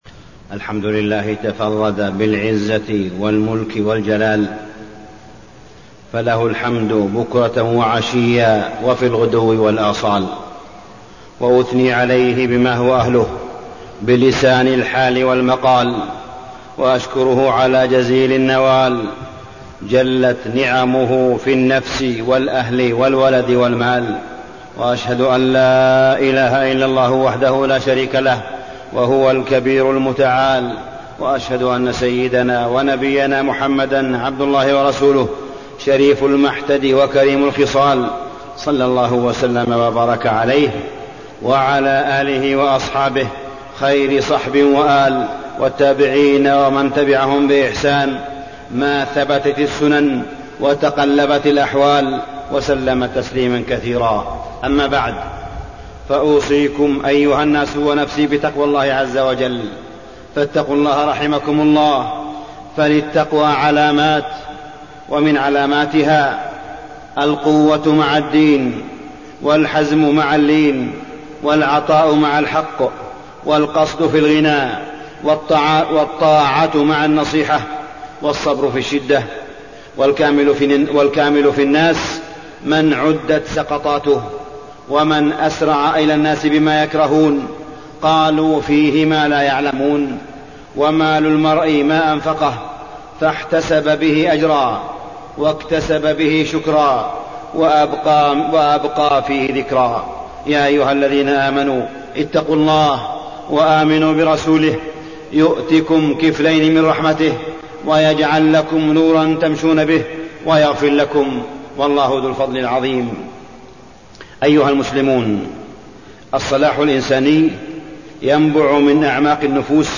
تاريخ النشر ٢٥ صفر ١٤٣٠ هـ المكان: المسجد الحرام الشيخ: معالي الشيخ أ.د. صالح بن عبدالله بن حميد معالي الشيخ أ.د. صالح بن عبدالله بن حميد حقيقة الزهد في الدنيا